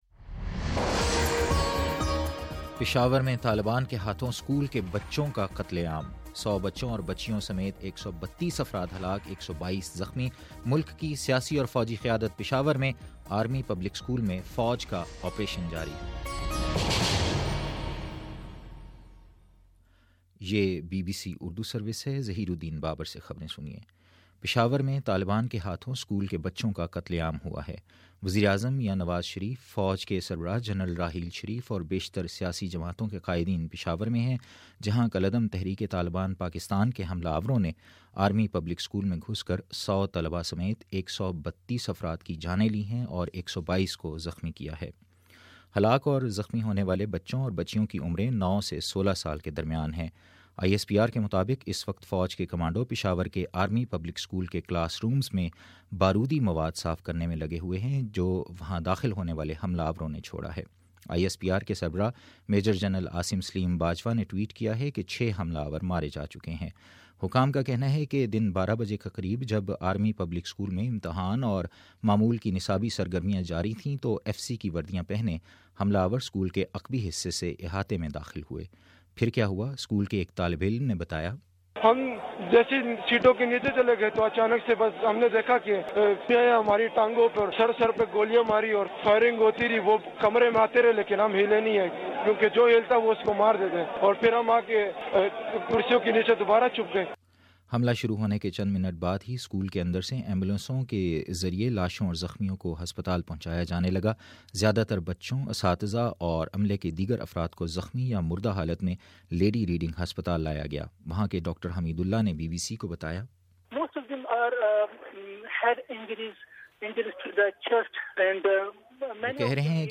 دسمبر16: شام چھ بجے کا نیوز بُلیٹن